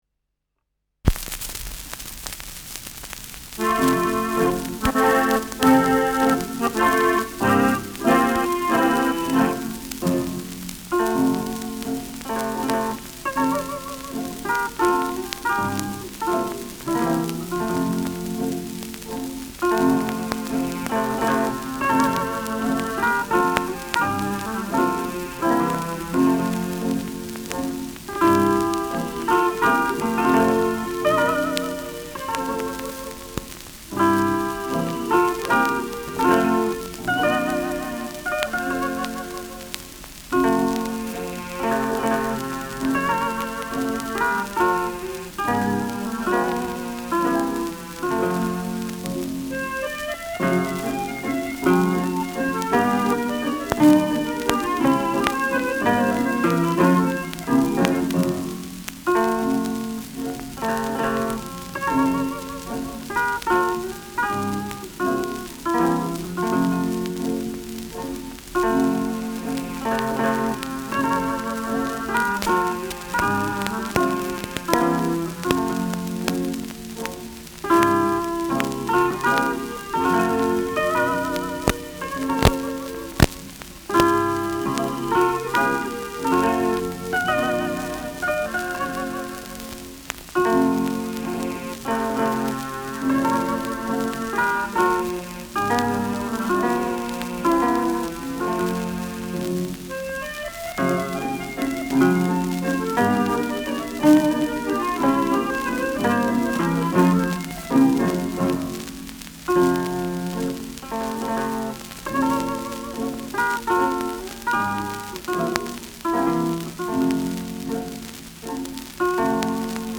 Schellackplatte
präsentes Rauschen : Knistern
[Berlin] (Aufnahmeort)
Stubenmusik* FVS-00016